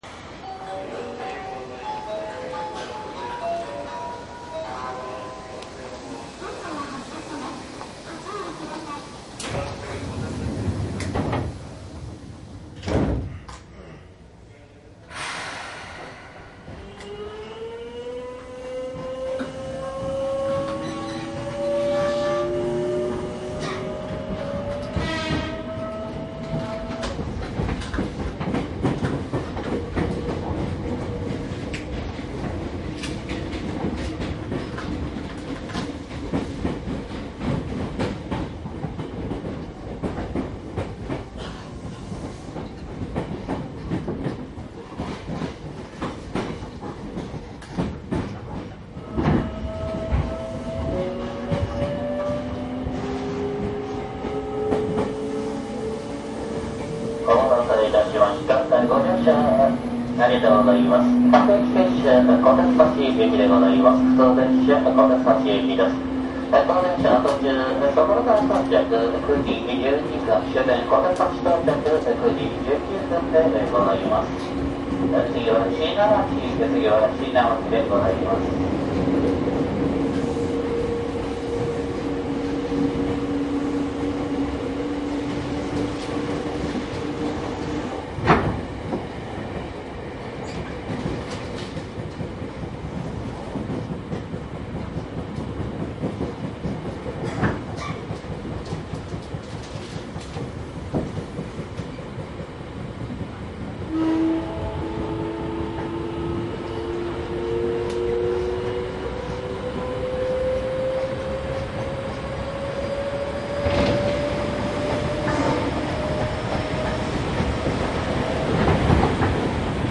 鉄道走行音CD 西武鉄道２０００系VVVF試作車 西武池袋線・狭山・山口線
西武池袋線 下り 各停 池袋→小手指